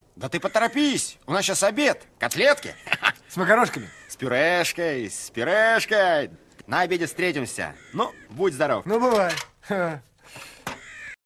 Диалог про макароны с пюрешкой (из мема)